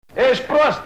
voce masc (afirma) - Esti Prost